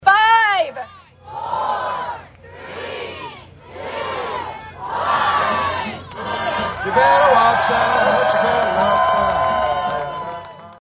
traditional countdown, the ninth annual Christmas In The Ville celebration at the Santa Fe Depot officially opened Sunday night.
Even with freezing temperatures and a brisk north breeze, a sizable crowd gathered to kick off the holiday season, enjoying carriage rides, ice skating, movies, a visit with Santa Claus and thousands of twinkling lights at Frank Phillips Park.
Countdown Christmas in the Ville.mp3